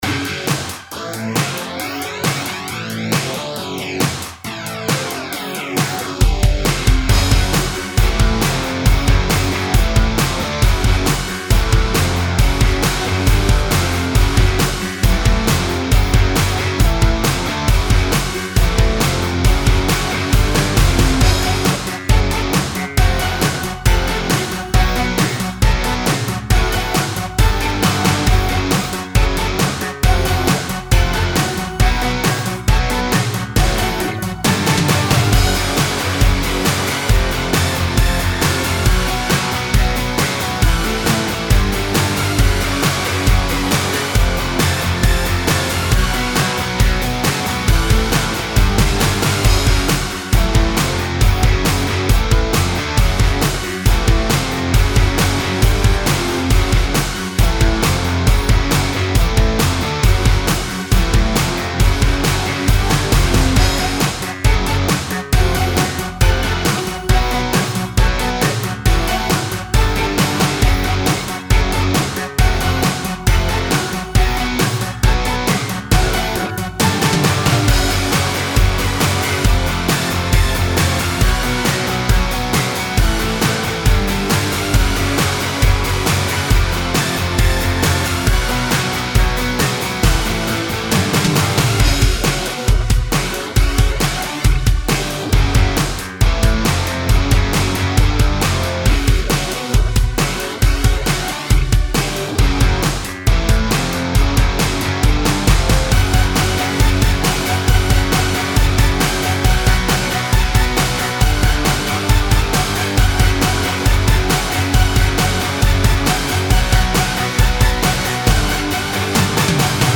this is quite the bop :)
Megaman vibes, I like it alot
Video Game
Loop